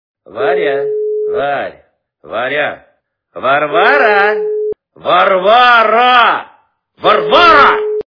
» Звуки » Именные звонки » Именной звонок для Варвары - Варя, Варь, Варя, Варвара, Варвара, Варвара
При прослушивании Именной звонок для Варвары - Варя, Варь, Варя, Варвара, Варвара, Варвара качество понижено и присутствуют гудки.